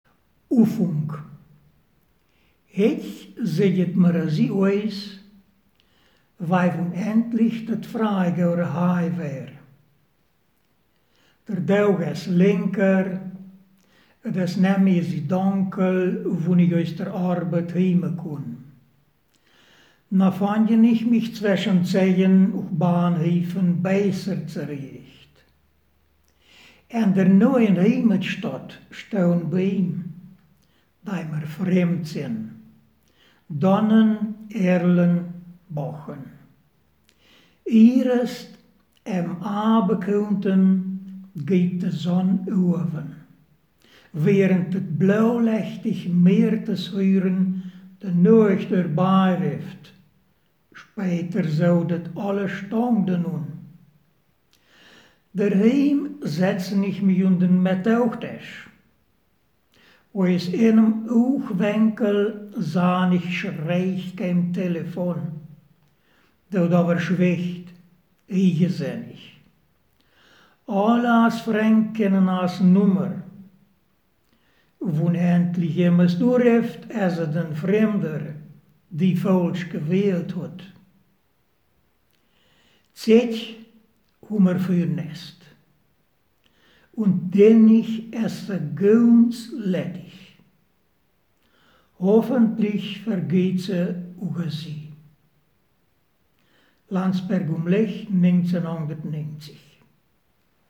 Ortsmundart: Kerz